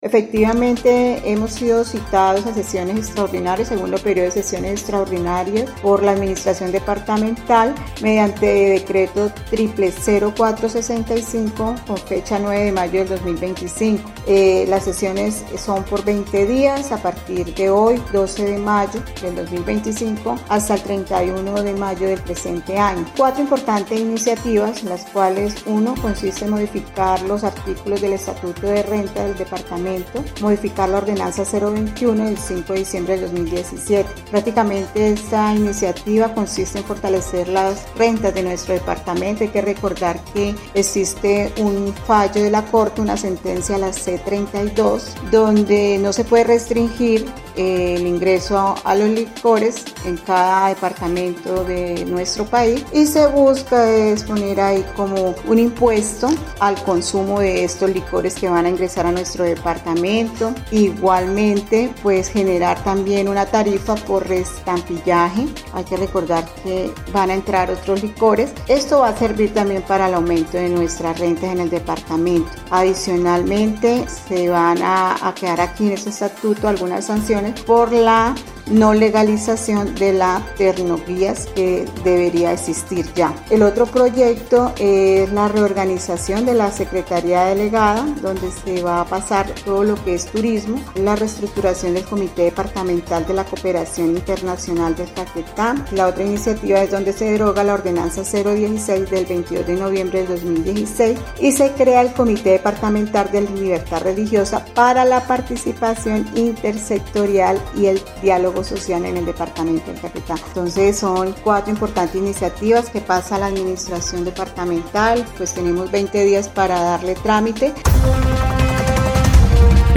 Libeth Amalia Gutiérrez Ardila, diputada conservadora y actual presidente de la mesa directiva de la asamblea departamental, explicó que serán cuatro los proyectos presentados por el ejecutivo, sobresaliendo la modificación del estatuto de rentas por el ingreso a la región de licores foráneos.